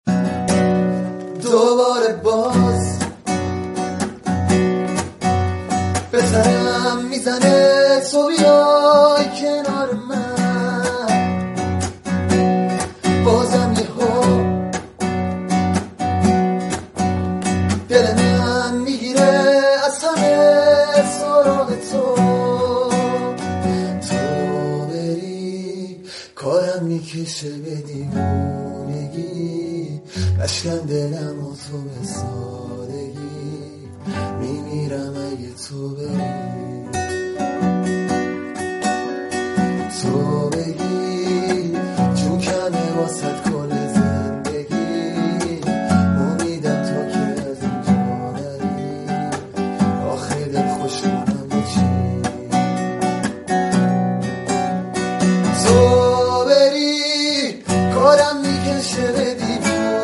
آهنگ جدید و غمگین ۹۹